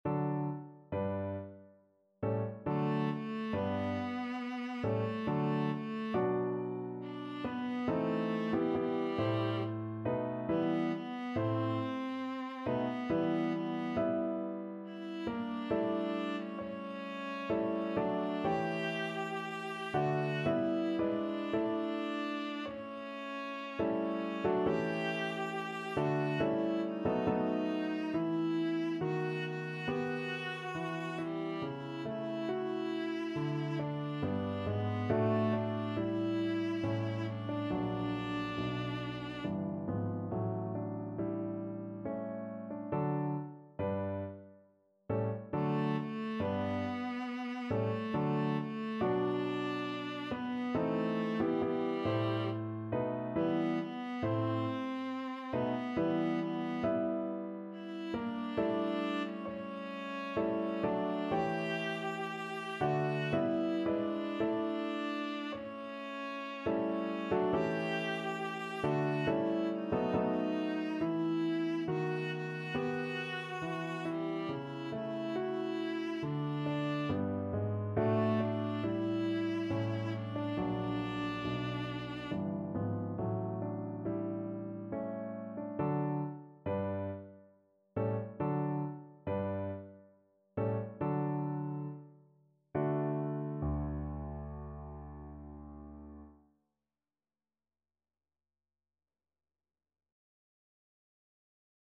3/4 (View more 3/4 Music)
Larghetto =69
Classical (View more Classical Viola Music)